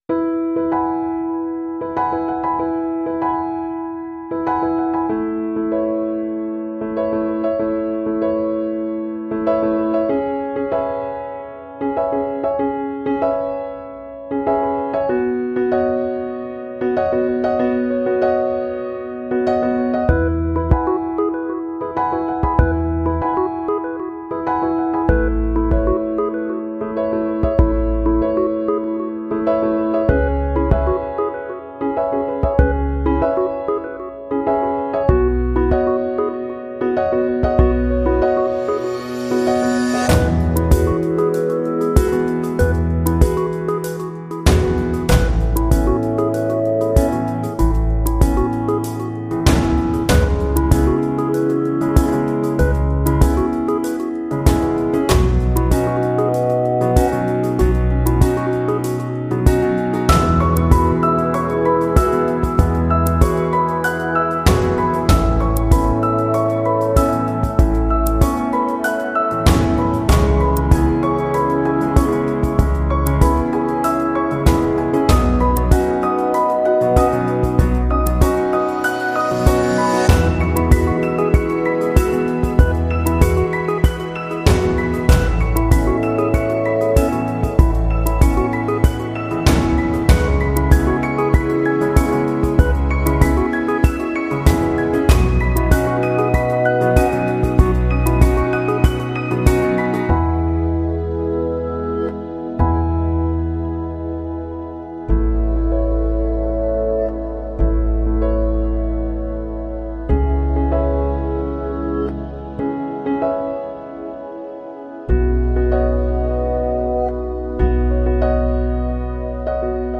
Солнечное и мелодичное звучание, сочетание нежного вокала
мягких инструментов создают уютную атмосферу.